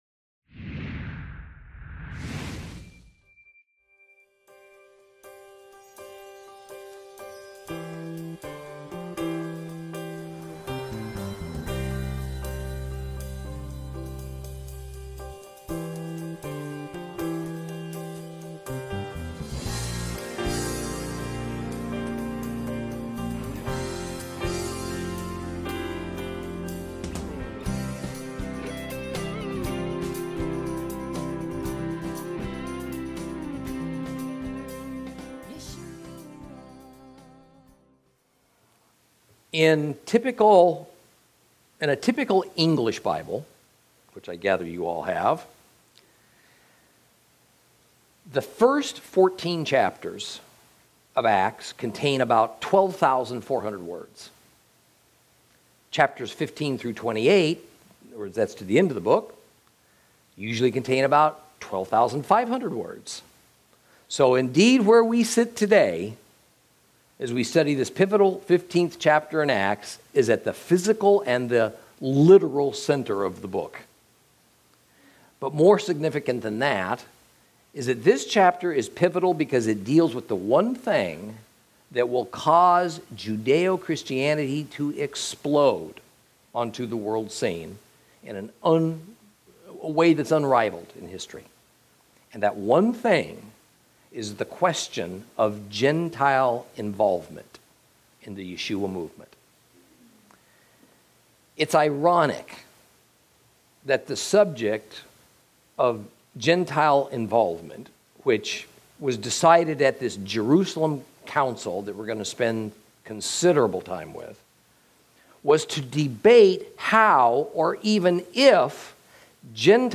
Acts Lesson 33 – Chapter 15